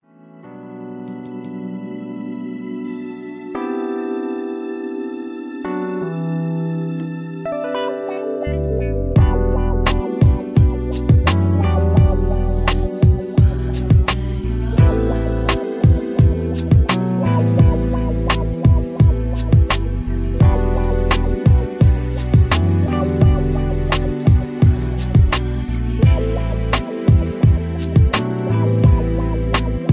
(Key-Em) Karaoke Mp3 Backing Tracks